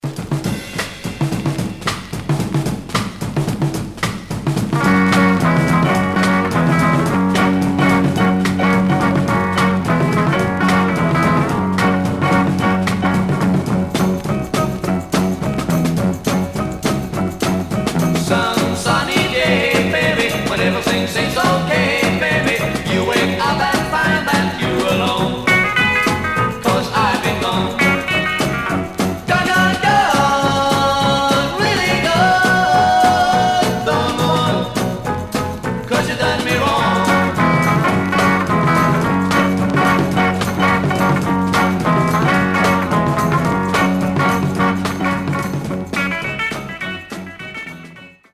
Much surface noise/wear
Mono
Garage, 60's Punk